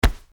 menu-selector.c2d53013cbf8c08d59f7.mp3